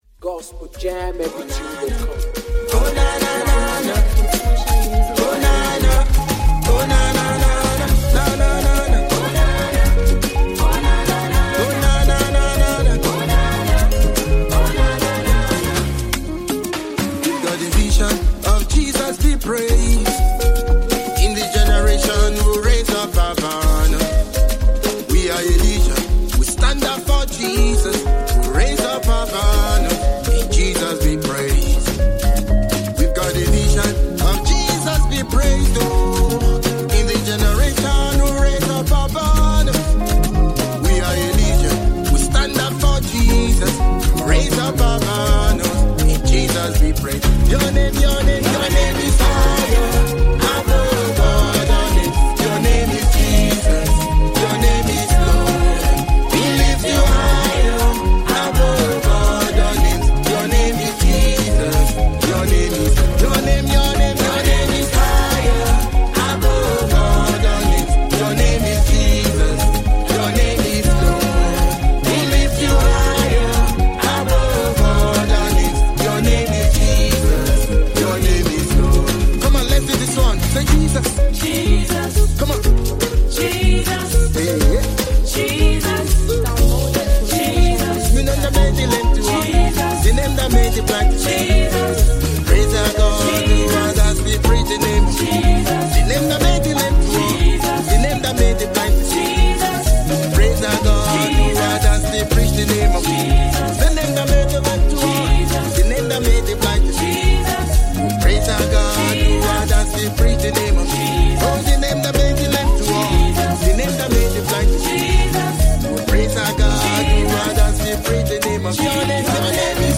powerful gospel sound
With heartfelt lyrics and a spirit-filled melody